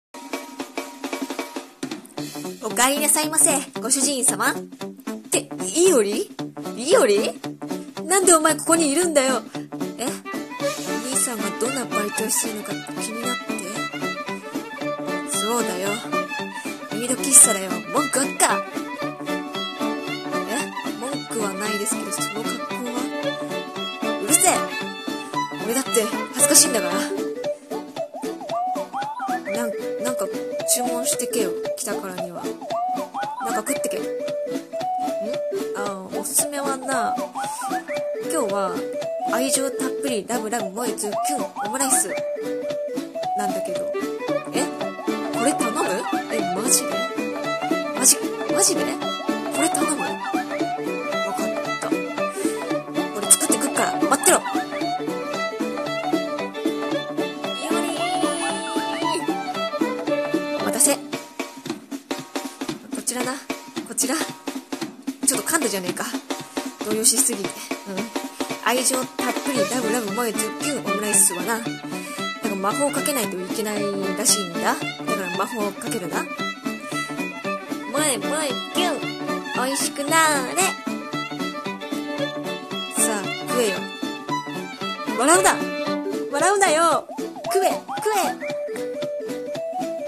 【声劇朗読】もしも和泉三月がメイド喫茶で働いてたら